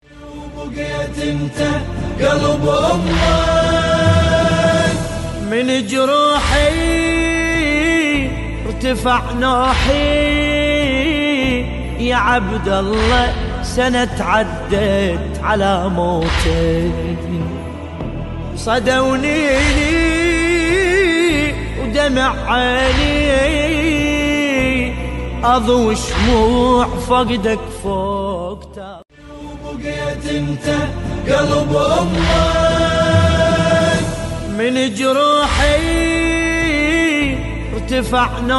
Category: Islamic Ringtones